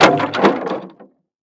branch.ogg